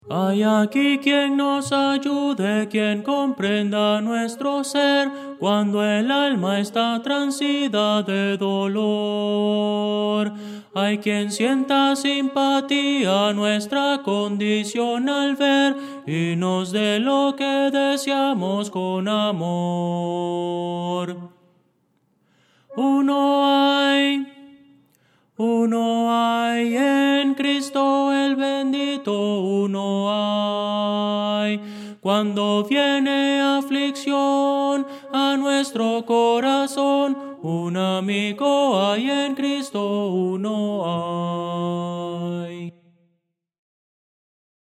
Voces para coro
Audio: MIDI